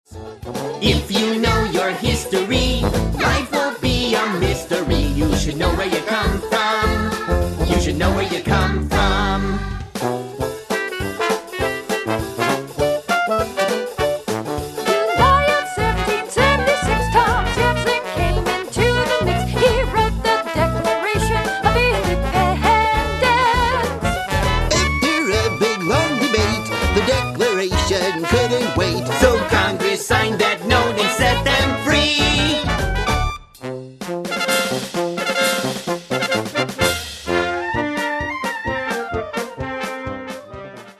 Children's Musical: